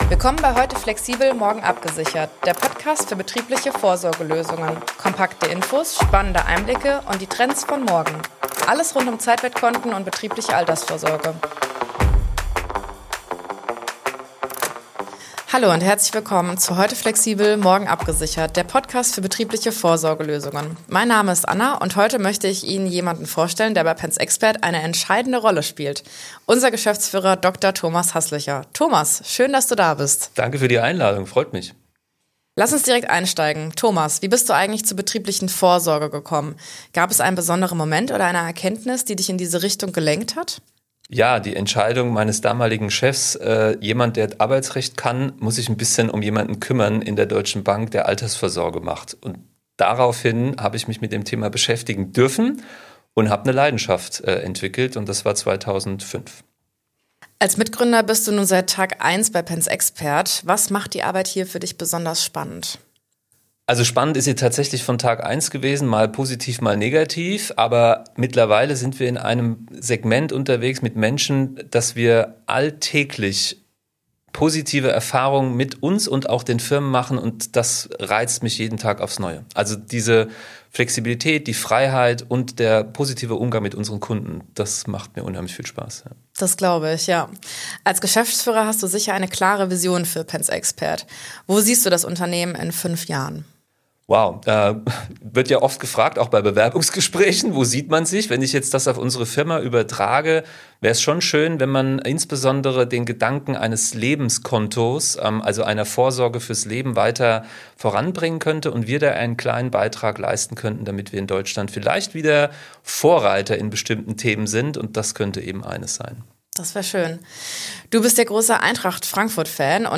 Ein offenes Gespräch über Verantwortung,